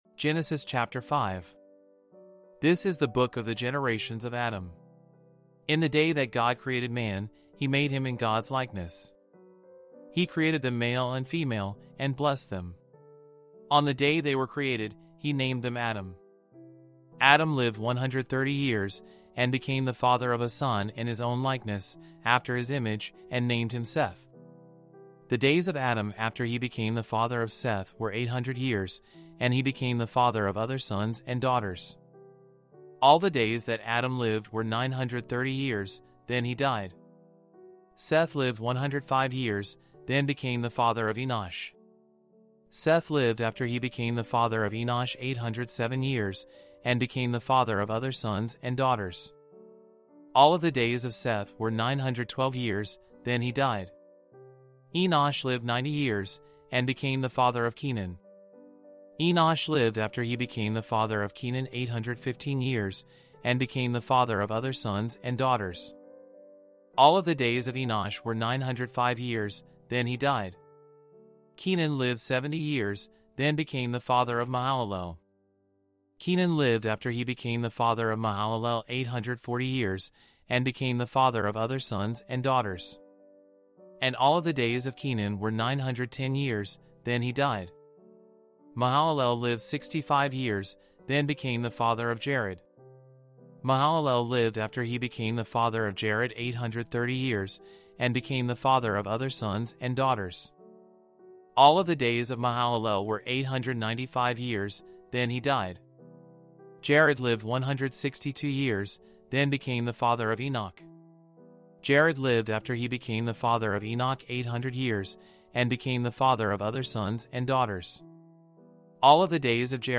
Audio Bible with Text